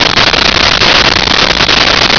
Sfx Amb Buzz Neon Loop
sfx_amb_buzz_neon_loop.wav